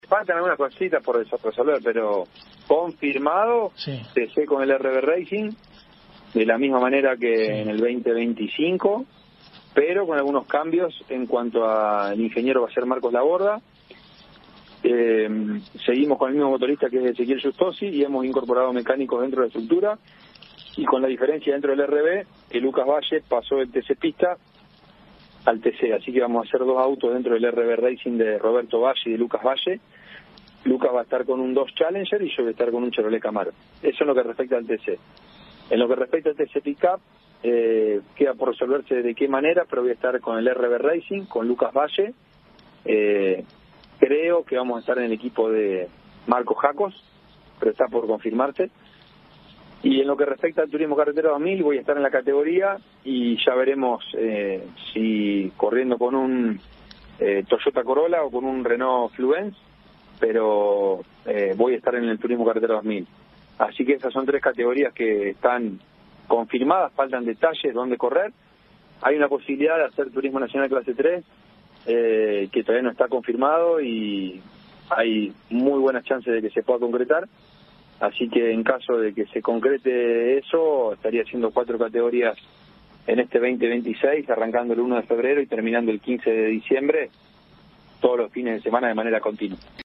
ESCUCHÁ LA PALABRA DE FACUNDO ARDUSSO EN CAMPEONES